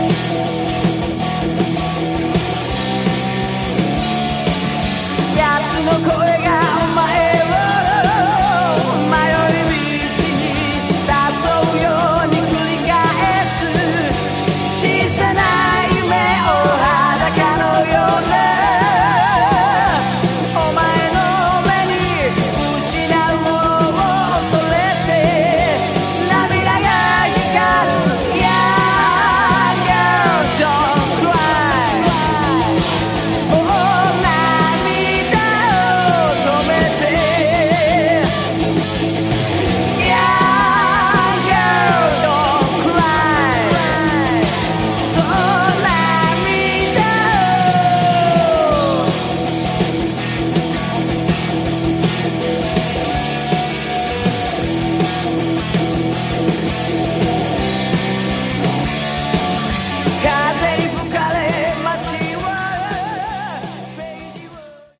Category: AOR
vocals
guitar
bass
drums
synthesizers